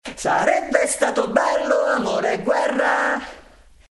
I also cut the “e” from “ed ascolto il suo silenzio”, applied a long reverb tail on it in my audio editor, reversed it and finally put it before. This is a trick to prepare tension towards the incoming line.
eh-reversed.mp3